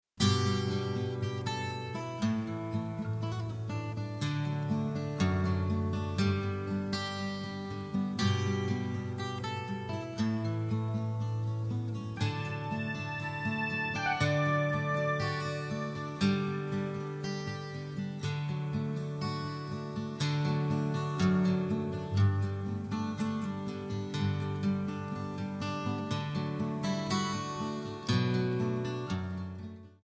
Backing track files: Country (2471)